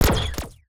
UIClick_Laser Double Impact 01.wav